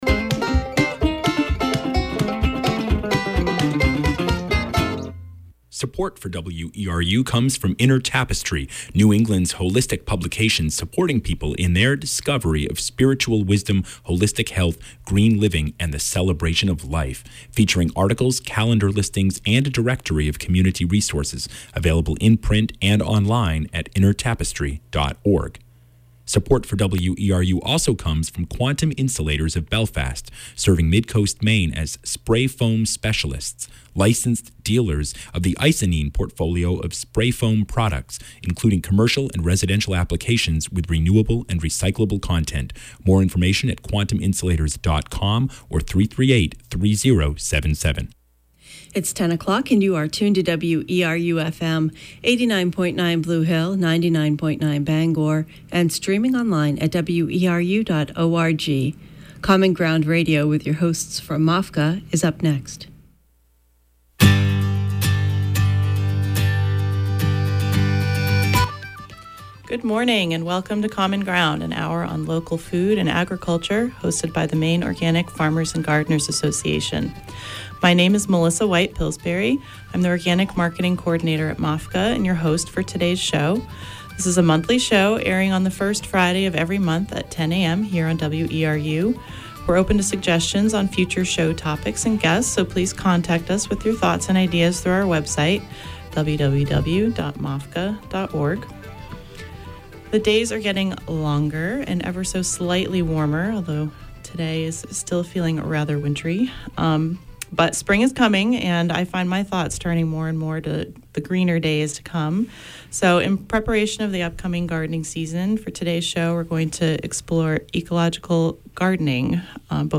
Common Ground Radio is an hour-long discussion of local food and organic agriculture with people here in the state of Maine and beyond.